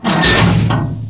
bucket.wav